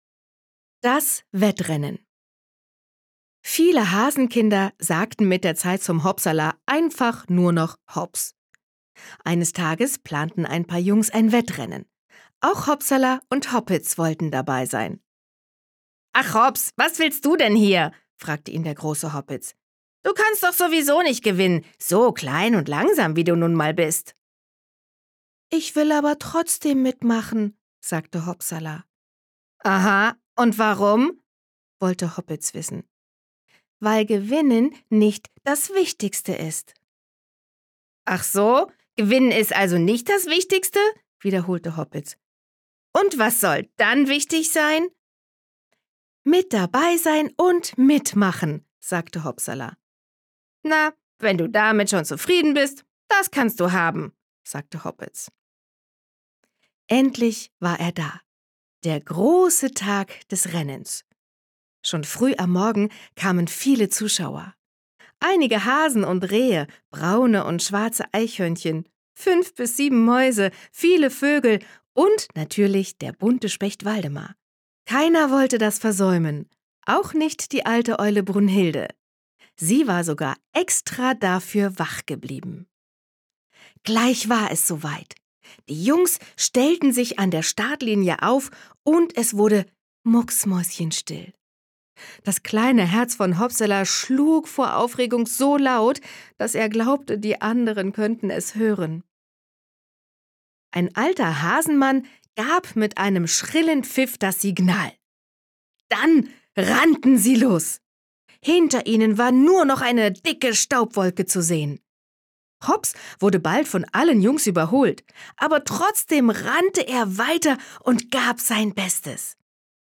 Andrea Ballschuh macht mit ihrer sympathischen Stimme diese schönen, spannenden und motivierenden Geschichten vom Knickohrhasen Hopsala zu einem besonderen Hörerlebnis.
Es hat mir sehr viel Spaß gemacht, diese Texte für euch zu lesen und im Tonstudio aufzunehmen.